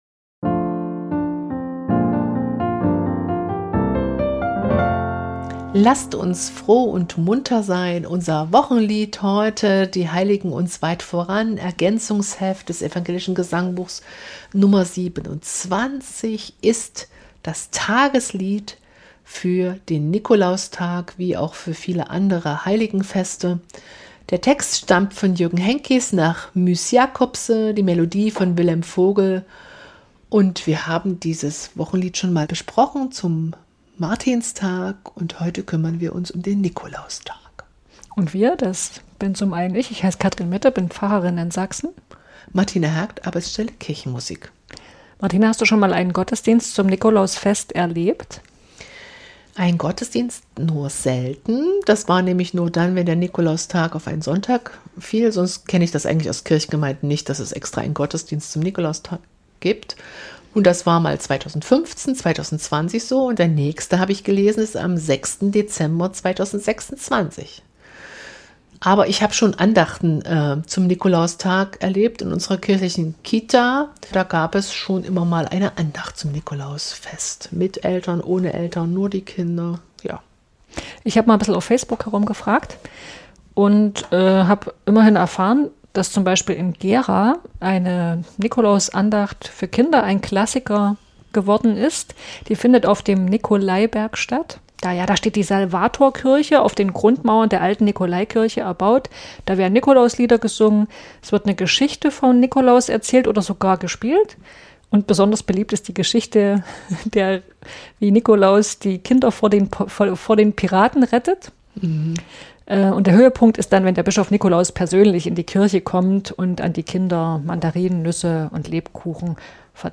Beschreibung vor 4 Monaten Einen besonderen und uns sehr lieben Gast hatten wir bei der Aufnahme zu dieser Folge in unserem "Studio" dabei